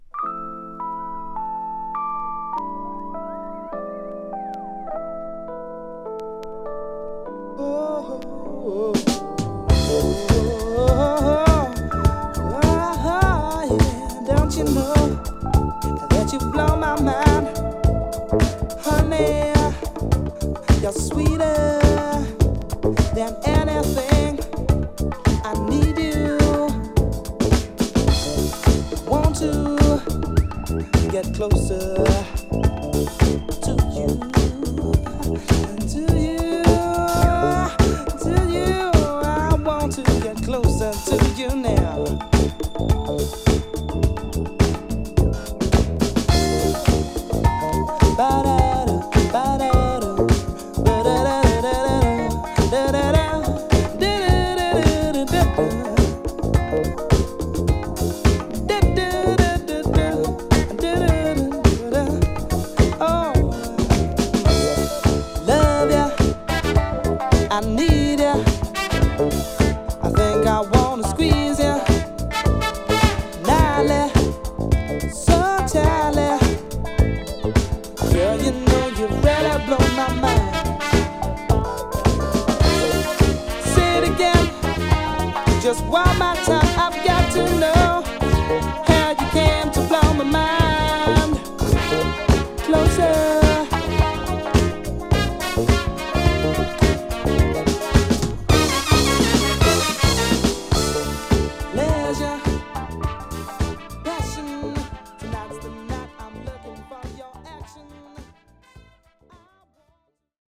> CROSSOVER/ACID JAZZ/CLUB JAZZ